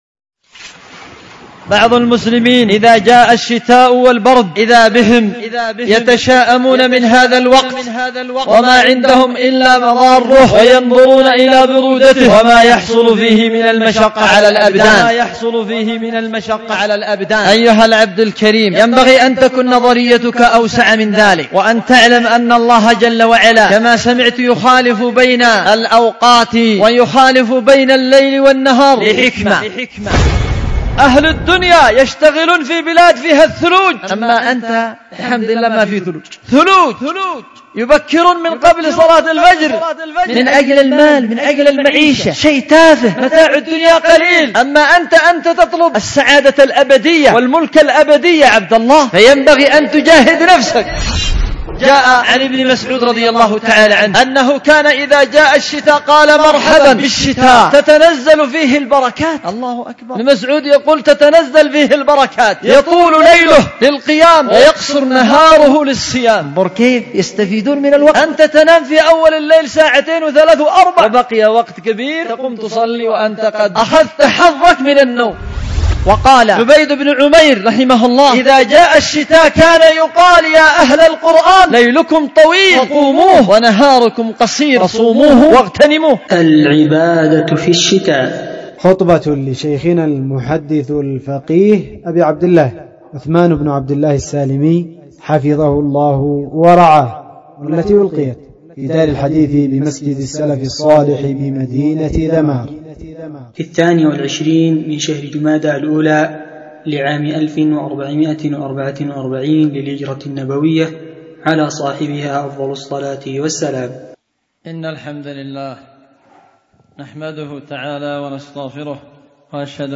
خطبة
في دار الحديث بمسجد السلف الصالح بذمار